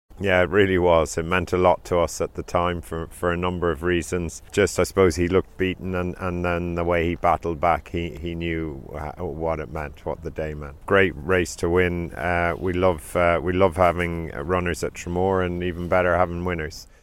De Bromhead says winning the New Year's Day Chase with Minello Indo in 2023 is a day he'll never forget: [Audio]